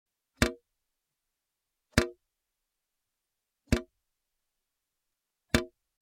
Water-dripping-into-an-empty-plastic-bucket-2.mp3